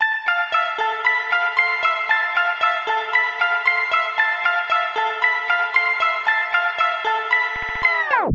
标签： 115 bpm Electro Loops Bass Synth Loops 1.41 MB wav Key : C
声道立体声